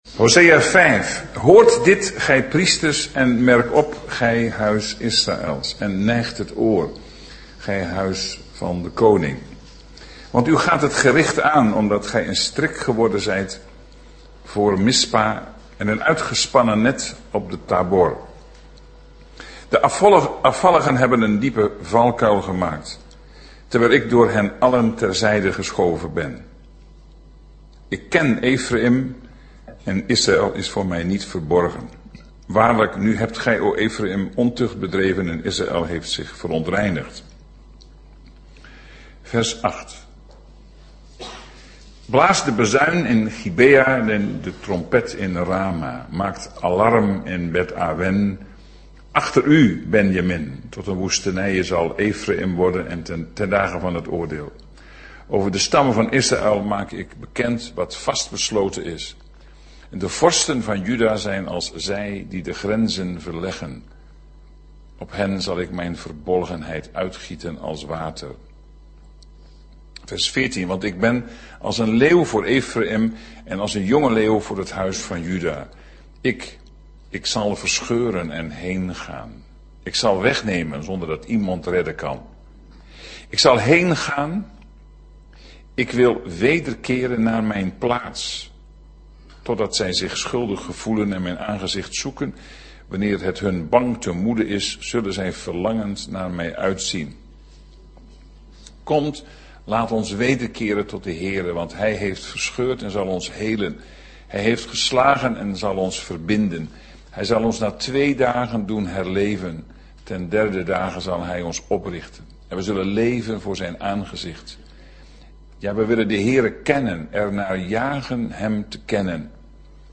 Deze preek is onderdeel van de serie: "Profeten in barre tijden"